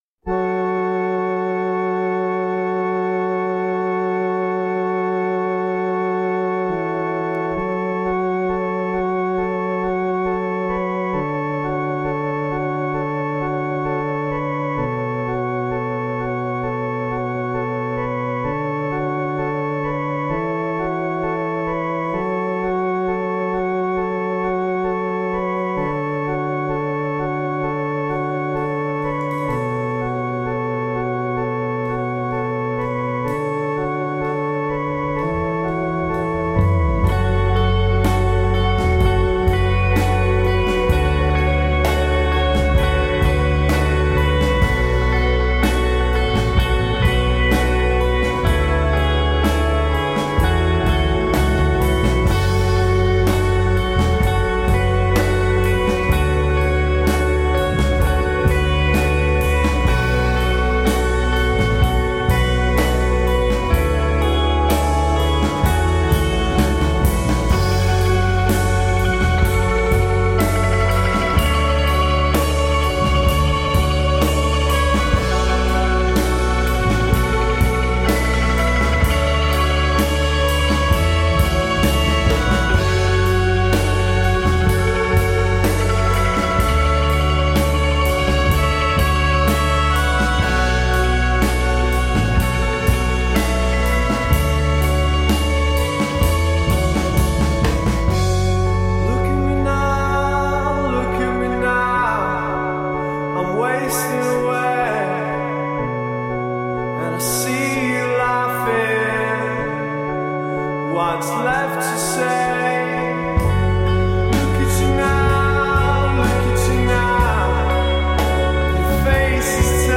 Tagged as: Alt Rock, Other, Indie Rock, Grungy Rock